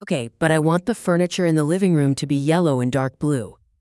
Text-to-Speech
Synthetic